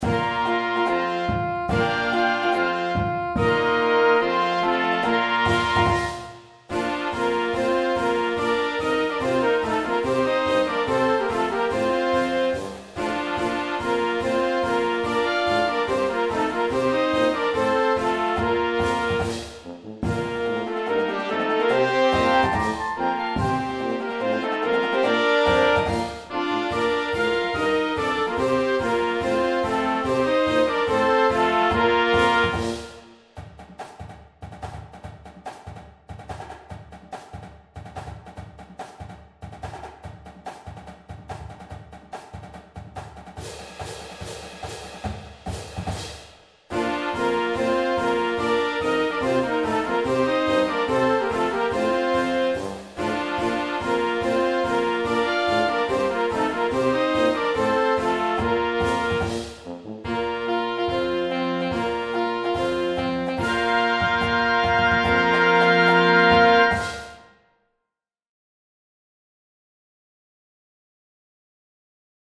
this piece is written in the style of a College fight song.